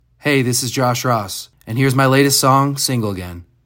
LINER Josh Ross (Single Again) 2